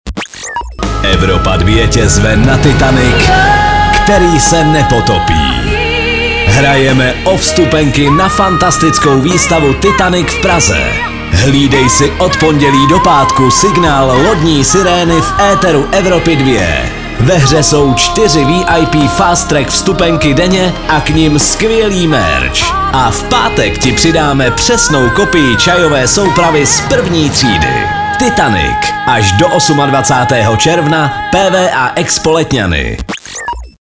liner_titanic_soutez_2026.mp3